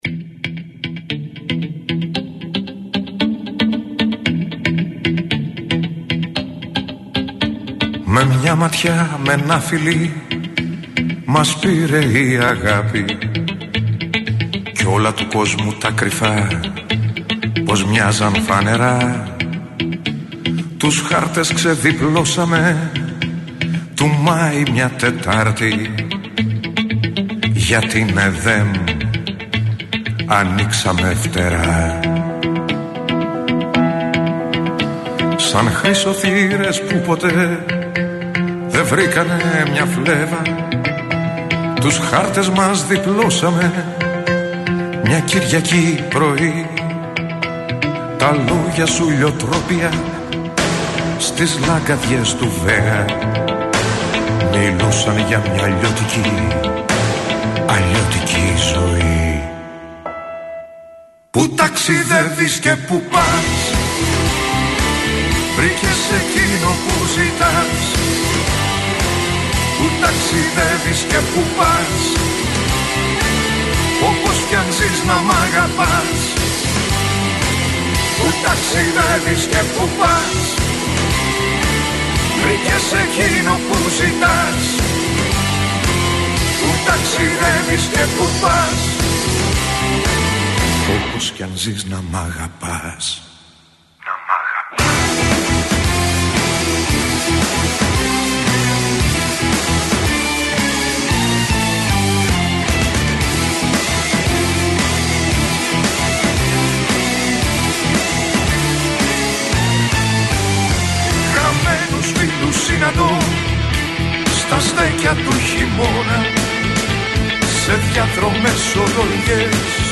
Στην εκπομπή του Νίκου Μπογιόπουλου στον Realfm 97,8 βρέθηκε καλεσμένος ο Βασίλης Παπακωνσταντίνου, που φέτος συμπληρώνει 50 χρόνια από την πρώτη του δισκογραφική δουλειά.